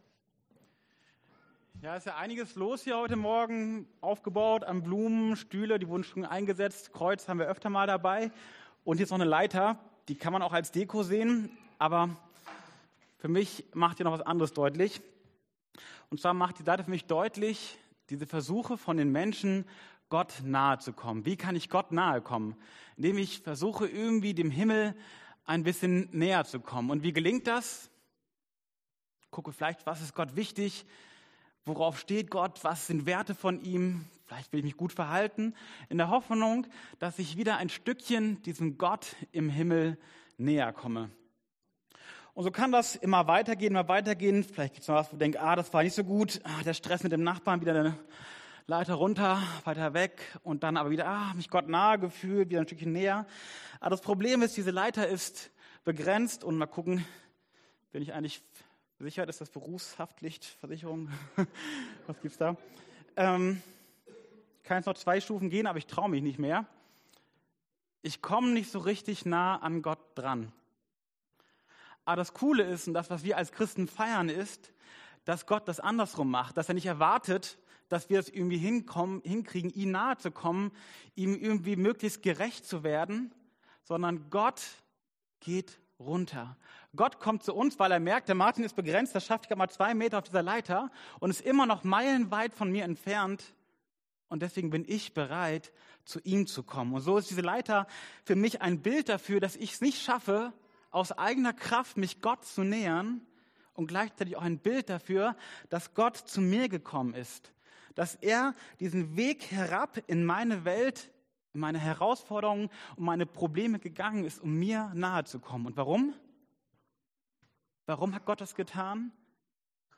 Predigt Themen: Heiliger Geist , Pfingsten « Jesus Christus